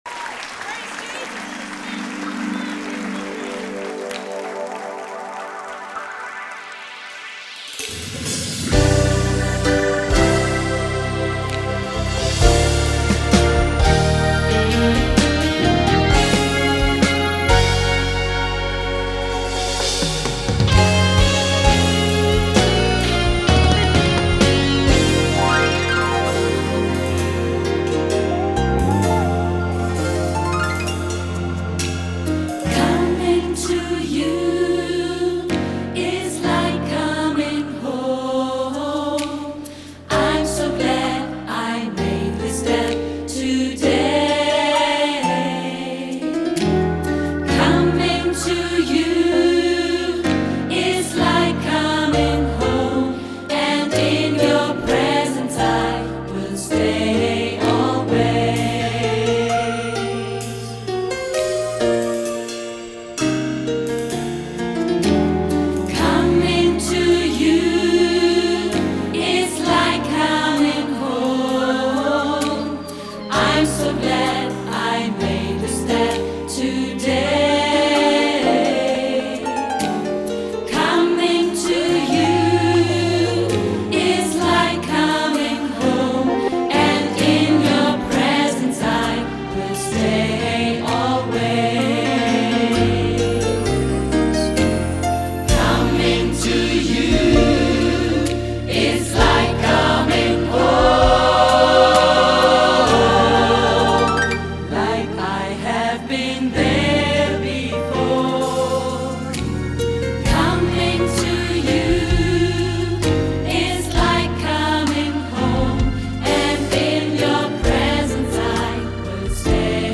• SAB, auch SSA, opt. Solo + Piano
GOSPELNOTEN